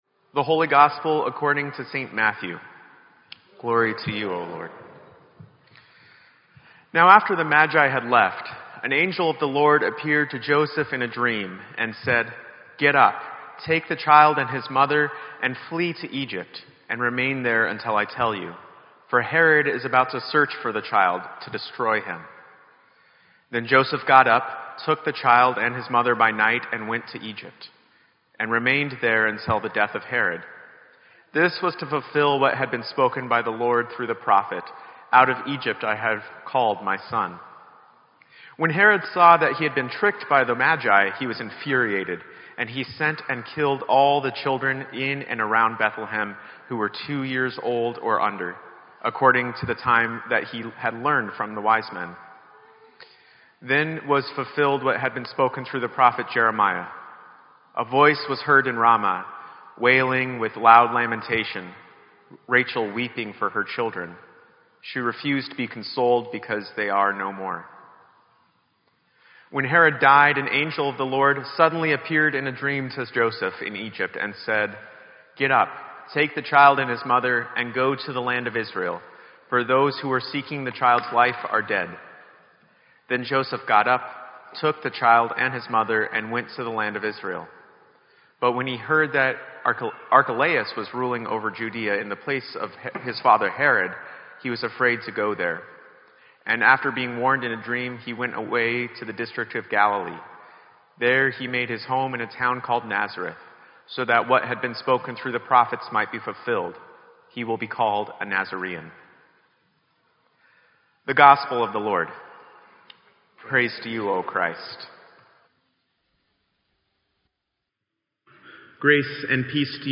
Sermon_1_1_17.mp3